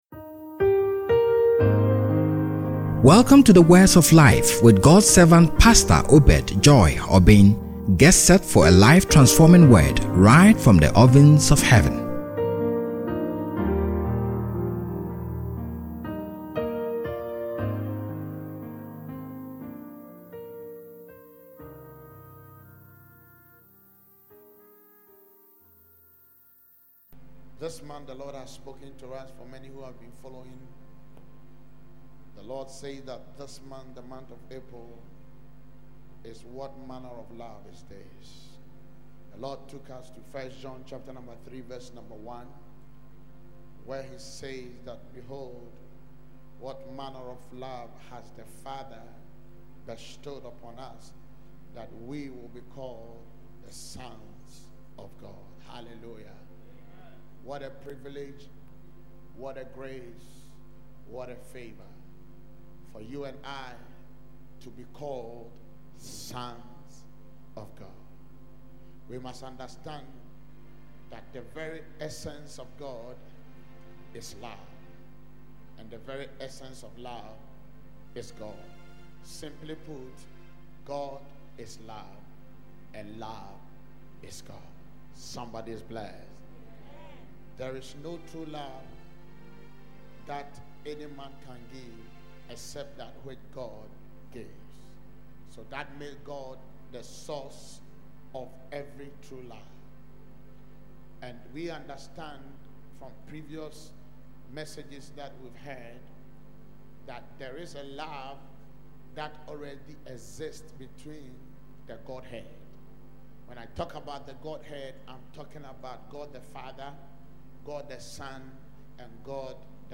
An exciting and a glorious service to witness once again with the atmosphere fully charged begetting instant healing and miracles.